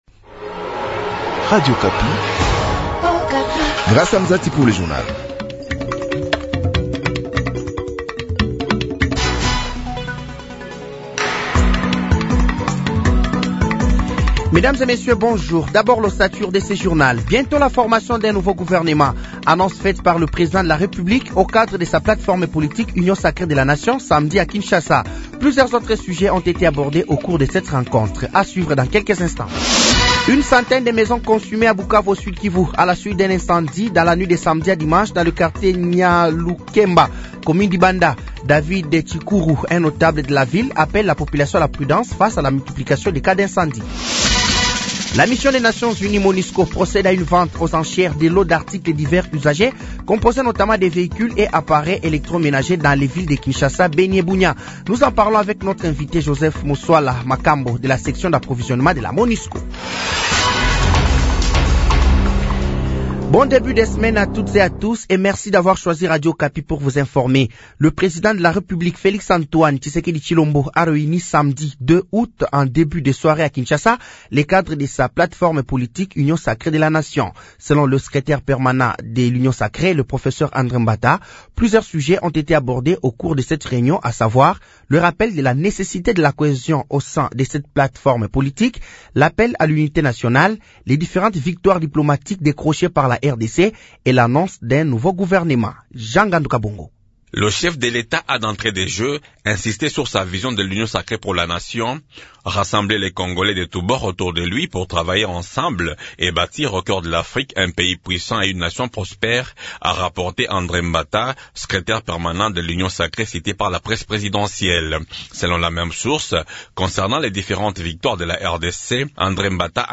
Journal français de 06h de ce lundi 04 août 2025